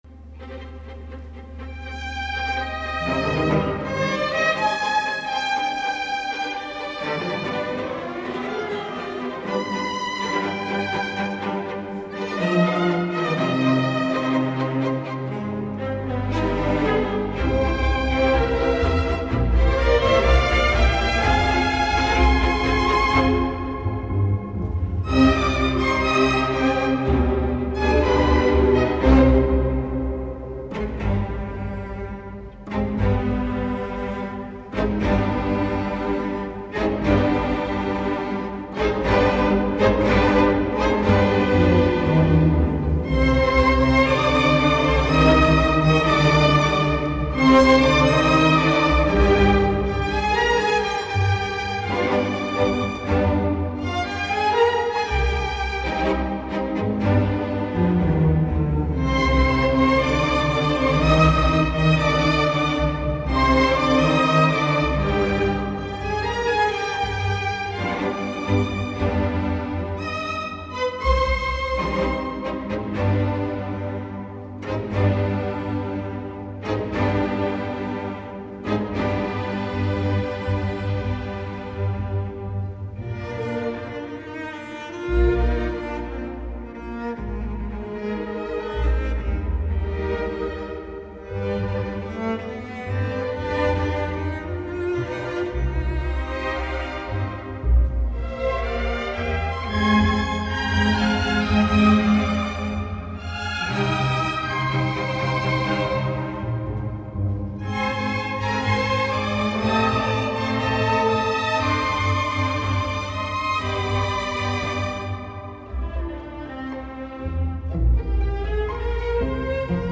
15η ΔΕBΘ Συνομιλία με τους συγγραφείς